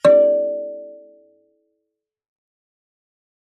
kalimba2_wood-D4-ff.wav